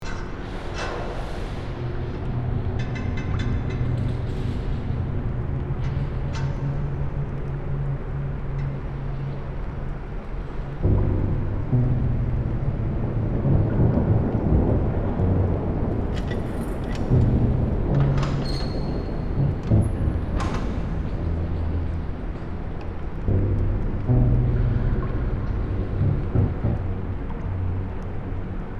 Download Free Horror Sound Effects | Gfx Sounds
Dilapitated-prison-ambience-tension-loop.mp3